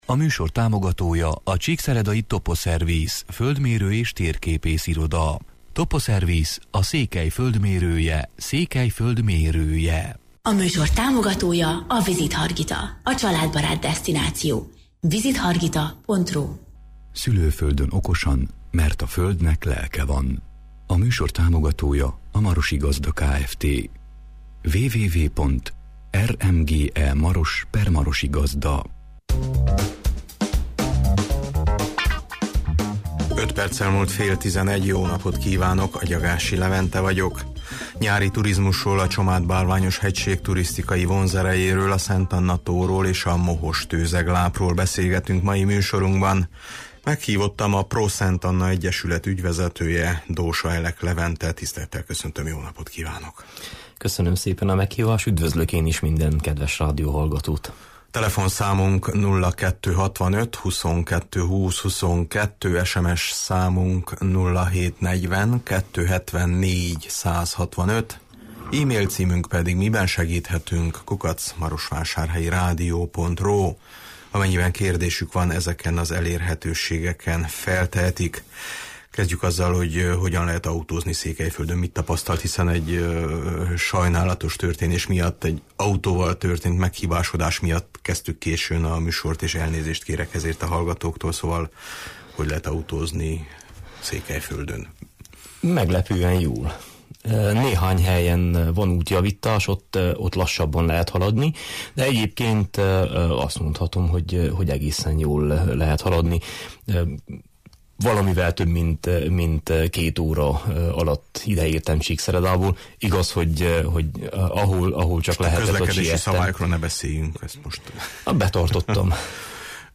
Nyári turizmusról, a Csomád-Bálványos hegység tursiztikai vonzerejéről, a Szent Anna-tóról és a Mohos tőzeglápról beszélgetünk mai műsorunkban.